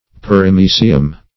Perimysium \Per`i*my"si*um\, n. [NL., fr. Gr. peri` about + my^s